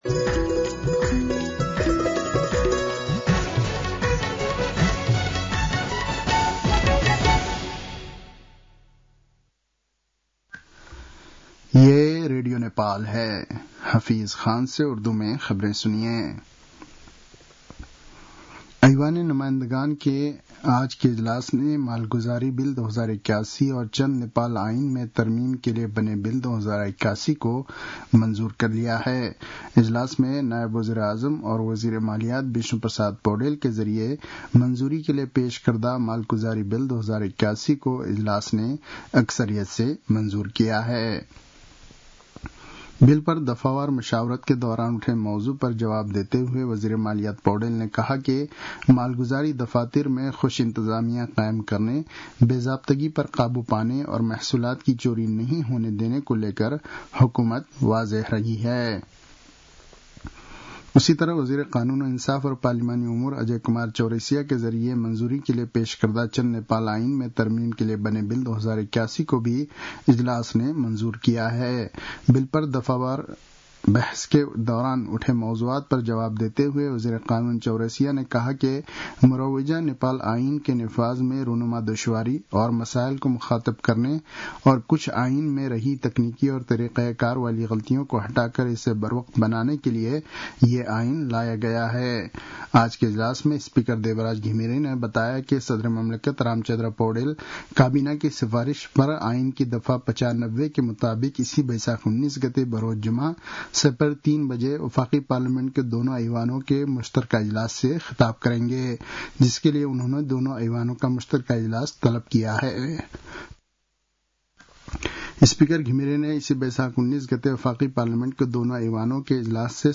उर्दु भाषामा समाचार : १६ वैशाख , २०८२